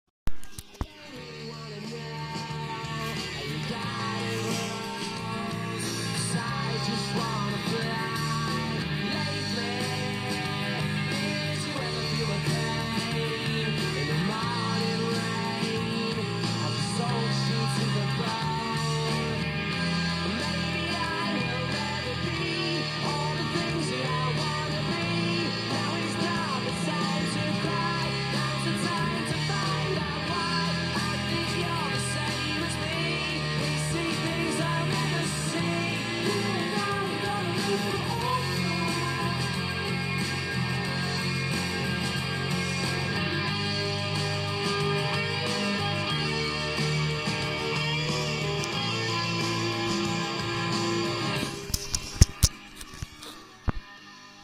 Piosenka z radia